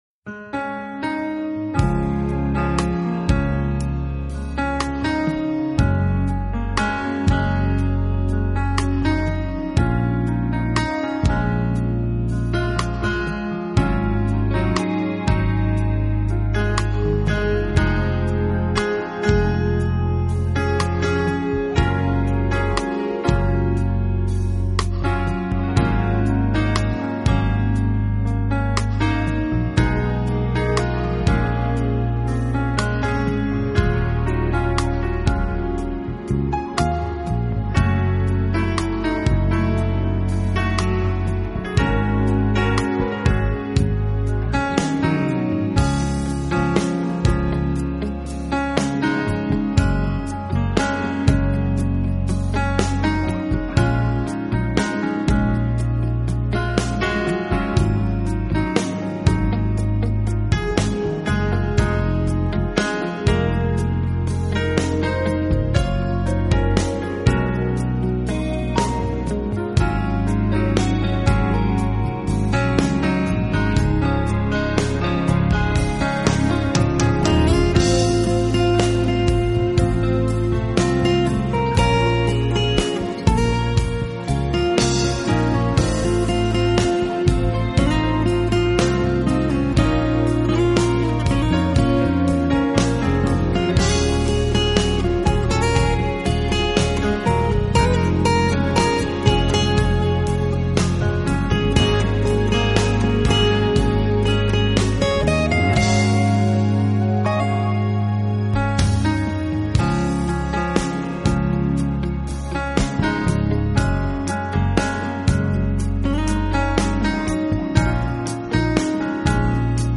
优美钢琴
优美的钢琴，清新、流畅、舒缓，无一不透露着自然的唯美、和谐。
闭上眼睛，聆听着这美妙的琴声，脑海里浮现一片美好的遐想。